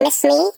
Sfx_tool_spypenguin_vo_rebuilt_03.ogg